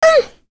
Lewsia_A-Vox_Damage_01.wav